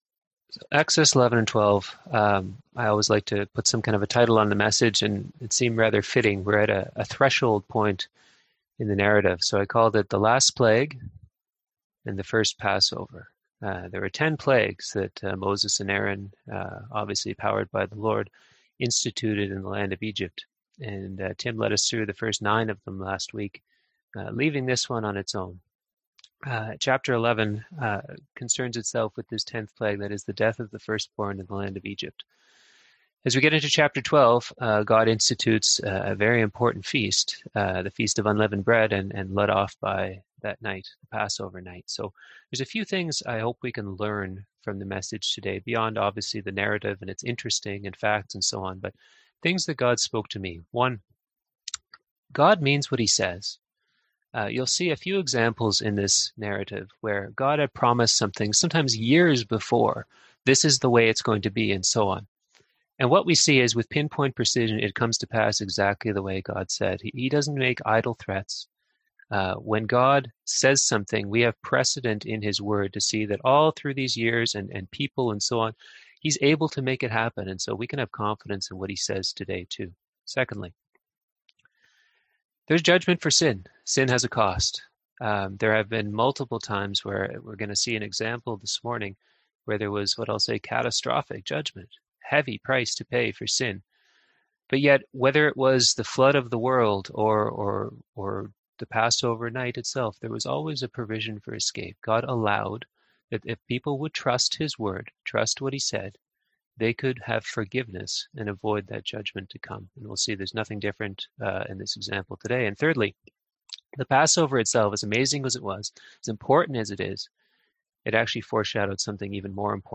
Passage: Exodus 11-12 Service Type: Sunday AM Topics: Death , Deliverance , Faith , Substitution